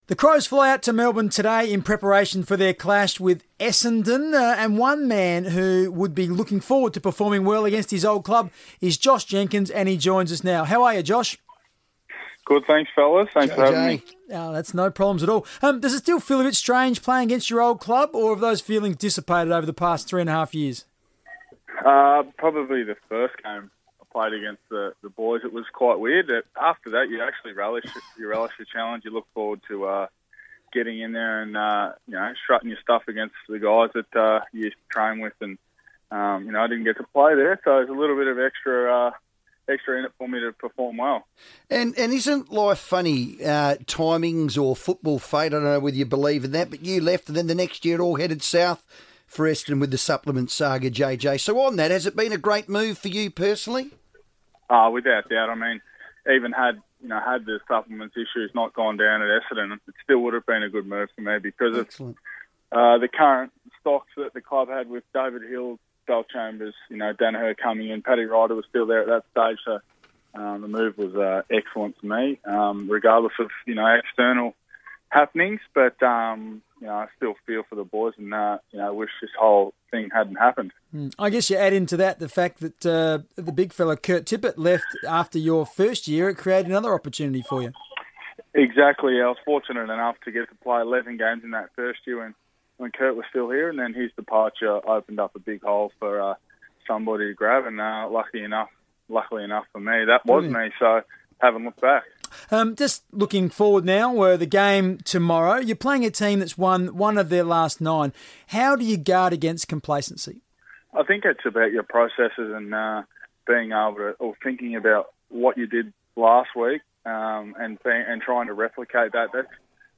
Crows forward Josh Jenkins spoke on the FIVEaa Sports Show ahead of Adelaide's clash with Essendon